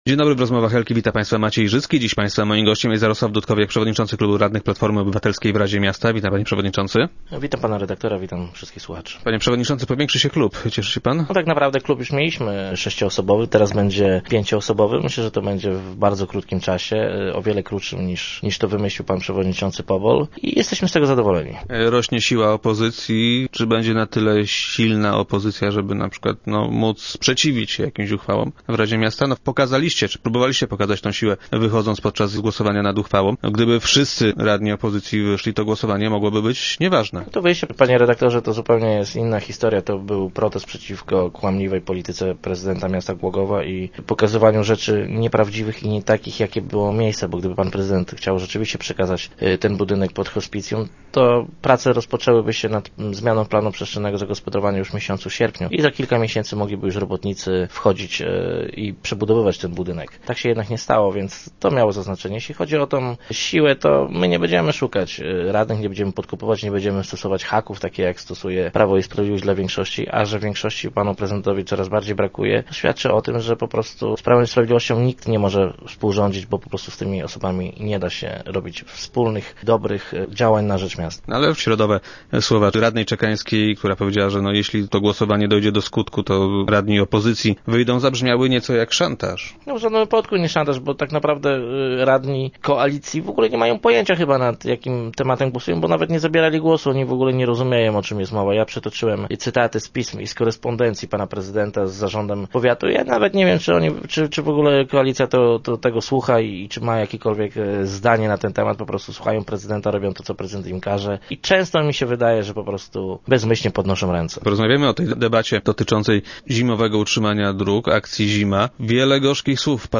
Jarosław Dudkowiak, przewodniczący klubu radnych PO i gość Rozmów Elki, skrytykował również prezydenta Głogowa, za jego zachowane podczas tej dyskusji.